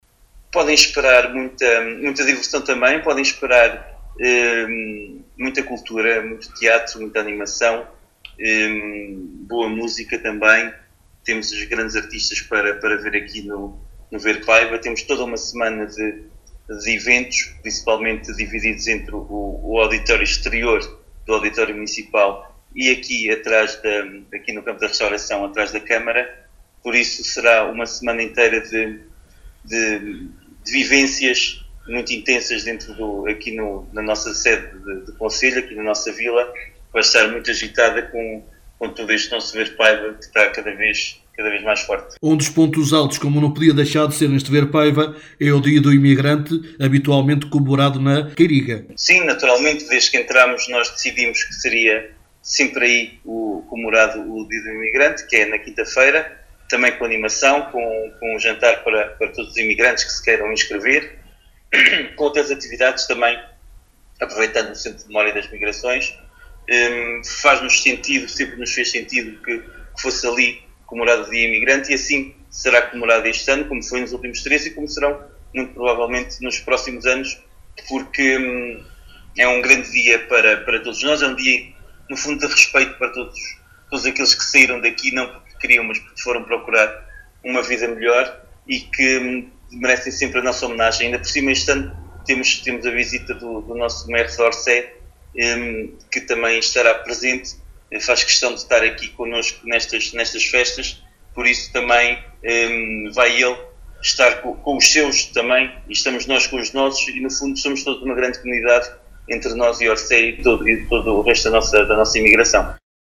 Paulo Marques, Presidente do Município de Vila Nova de Paiva, em declarações à Alive FM, diz que os visitantes “podem esperar muita diversão, cultura, teatro, animação e boa música…”.